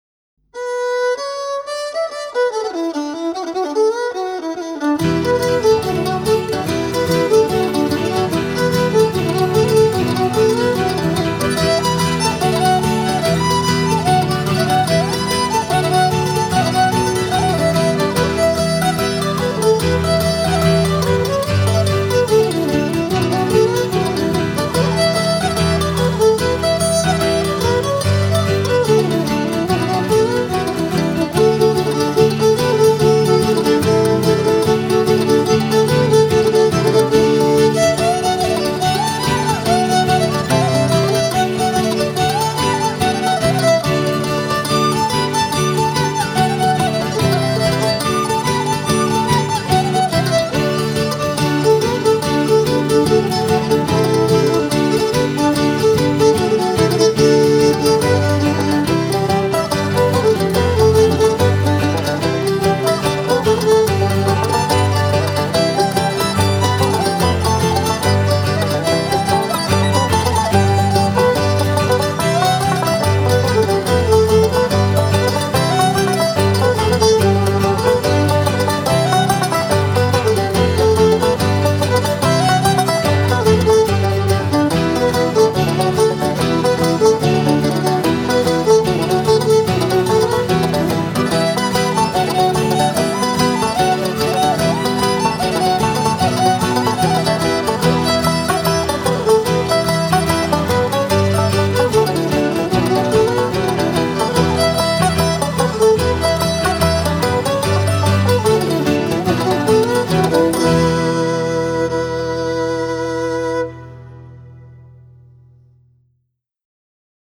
fiddle